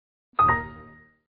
На этой странице собраны звуки Windows 11 — современные системные аудиоэффекты из новой версии ОС.
Звук ошибки Windows XP